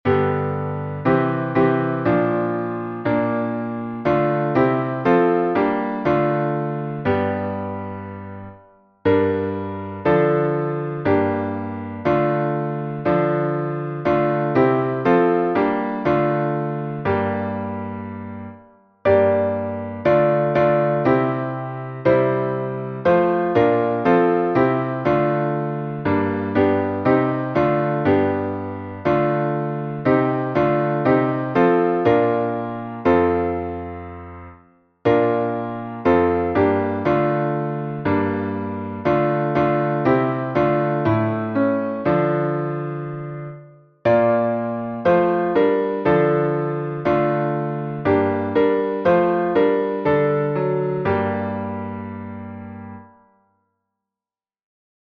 salmo_23A_instrumental.mp3